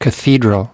2 cathedral (n) /kəˈθiːdrəl/ Nhà thờ, Chính tòa, Nhà thờ lớn